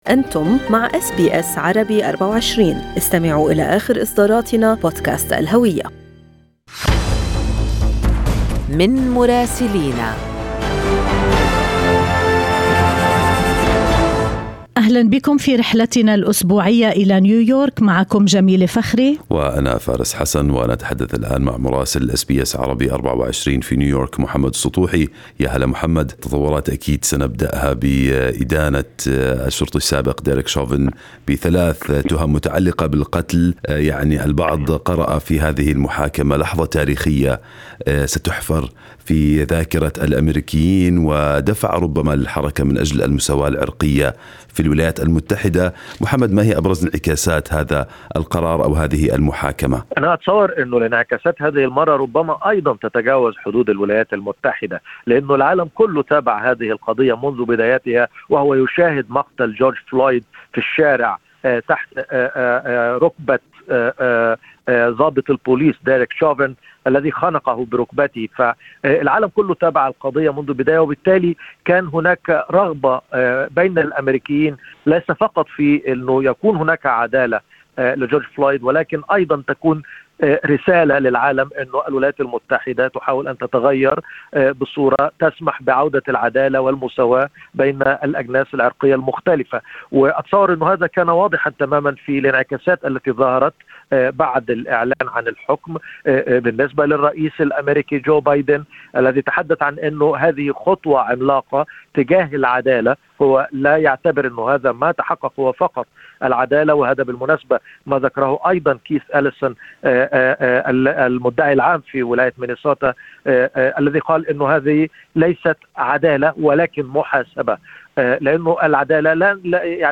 من مراسلينا: أخبار الولايات المتحدة الأمريكية في أسبوع 22/4/2021